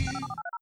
сайт, который при переходе на него выдавало .wav файл, в котором была найдена DTMF метка, и соответственно при использовании DTMF Decoder'а был разгадан пароль от сайта, и после чего все обсуждения ARG перетекли в этот чат.